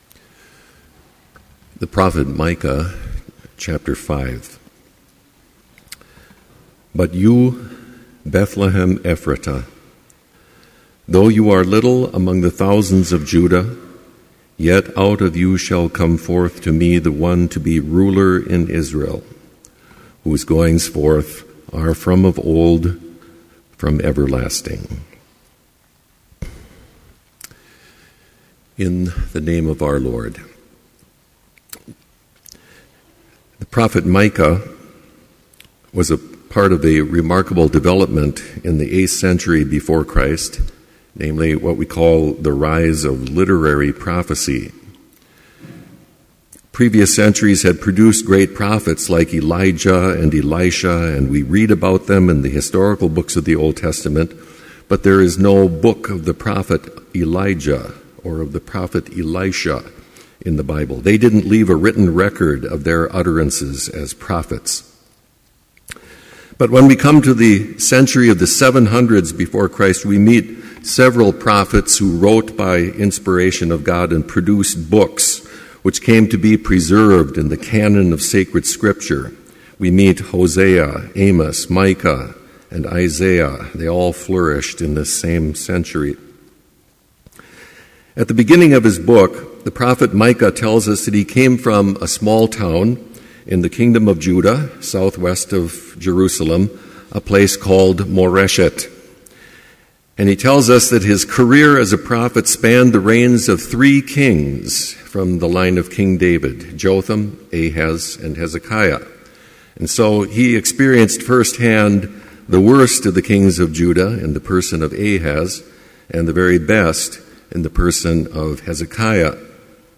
Complete Service
vv. 1-4 – Children
• Homily
v. 3 - Soloist
This Chapel Service was held in Trinity Chapel at Bethany Lutheran College on Wednesday, December 19, 2012, at 10 a.m. Page and hymn numbers are from the Evangelical Lutheran Hymnary.